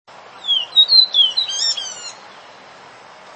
繡眼雀鶥 Alcippe morrisonia morrisonia
南投縣 鹿谷鄉 溪頭
錄音環境 雜木林
單隻雄鳥歌聲
收音: 廠牌 Sennheiser 型號 ME 67